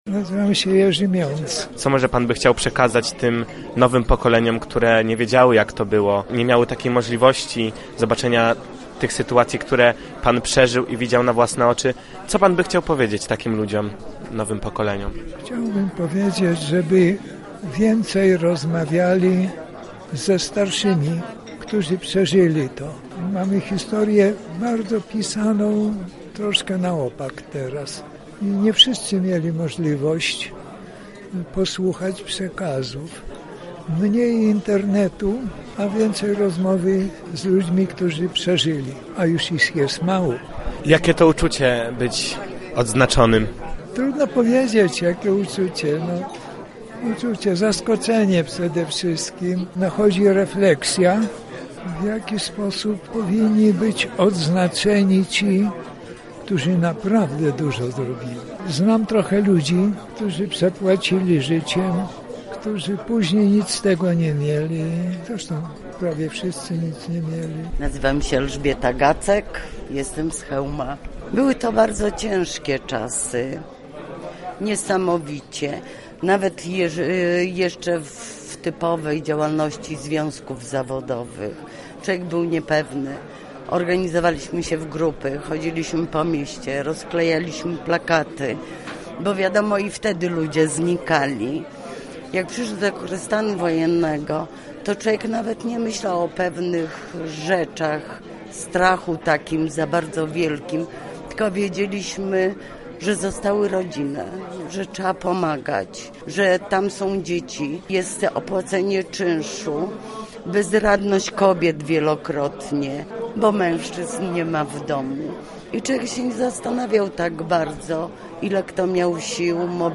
W Lubelskim oddziale Instytutu Pamięci Narodowej uhonorowani zostali najbardziej zasłużeni działacze opozycji demokratycznej. Łącznie 20 osób, w tym 4 pośmiertnie, zostało dziś odznaczonych Krzyżem Wolności i Solidarności.